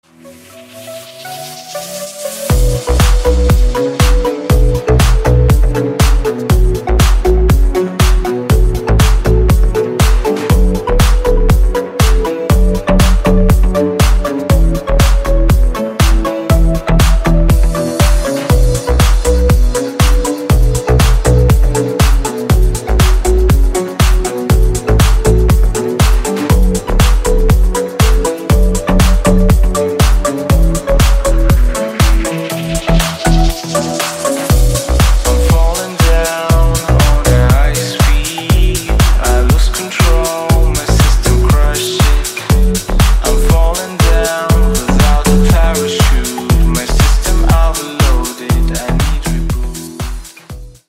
• Качество: 160, Stereo
deep house
красивый мужской голос
Electronic
спокойные